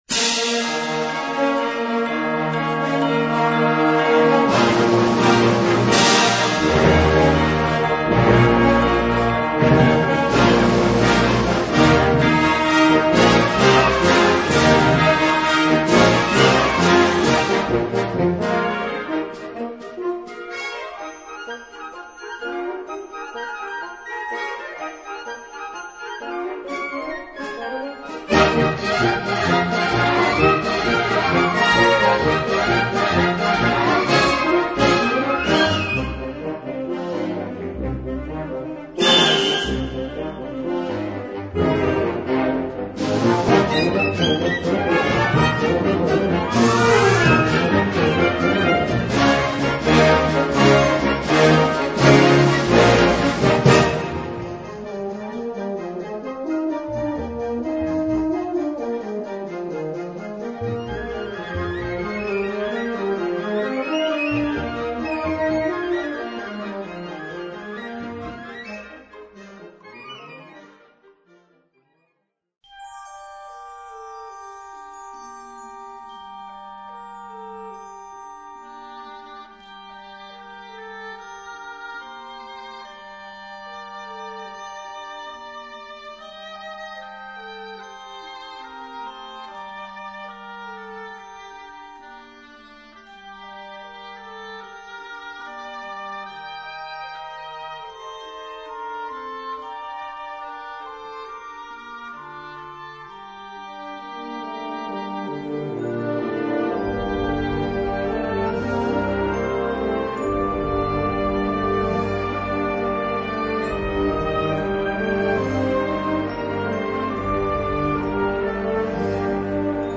Kategorie Blasorchester/HaFaBra
Unterkategorie Zeitgenössische Bläsermusik (1945-heute)
Besetzung Ha (Blasorchester)